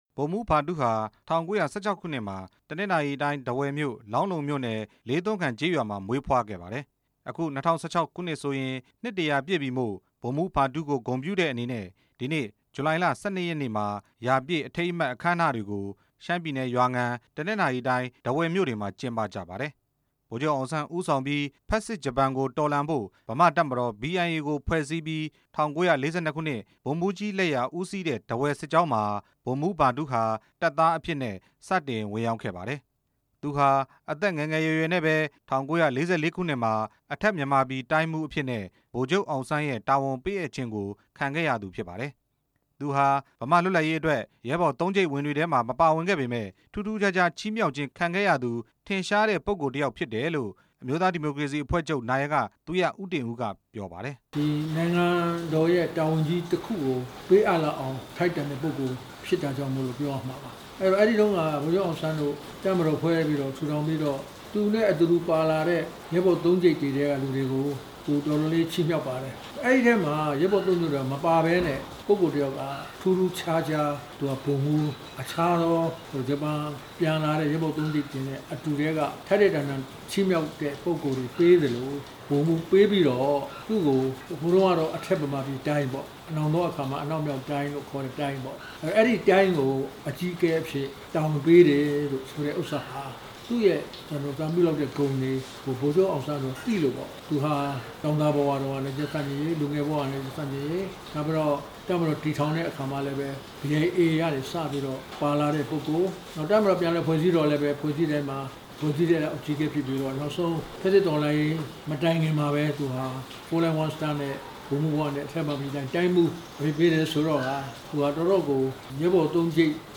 ဗိုလ်မှူးကြီးဗထူး အကြောင်း သူရဦးတင်ဦးနဲ့ မေးမြန်းချက်
ဒီကနေ့ ဇူလိုင်လ ၁၂ ရက်နေ့ဟာ ဖက်ဆစ်ဂျပန်တော်လှန်ရေးမှာ ကျဆုံးသွားခဲ့တဲ့ ဗိုလ်မှူးကြီး ဗထူး နှစ်တစ်ရာပြည့်နေ့ ဖြစ်ပါတယ်။ ဗိုလ်မှူးကြီးဗထူး ကို သိခဲ့တဲ့ အမျိုးသားဒီမိုကရေစီအဖွဲ့ချုပ် နာယက သူရဦးတင်ဦးက ဗိုလ်မှူးကြီး ဗထူးအကြောင်းကို ပြောပြထားပါတယ်။